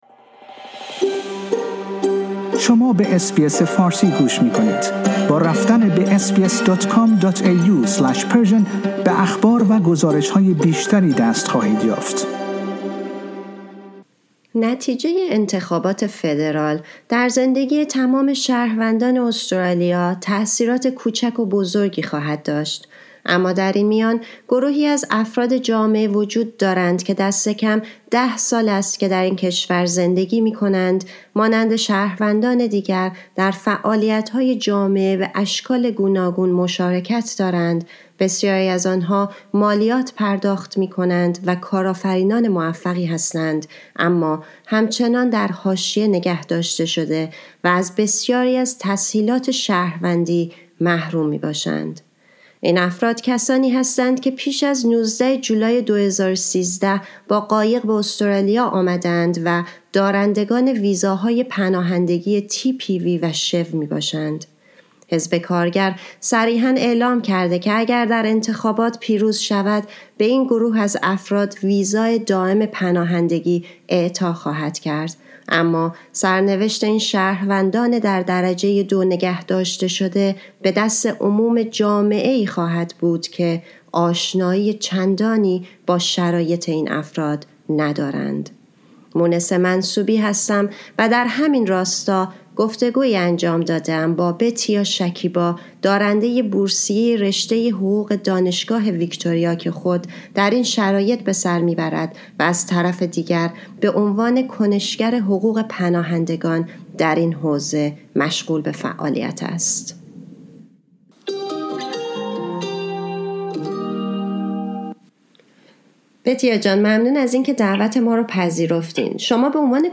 در این گفتگو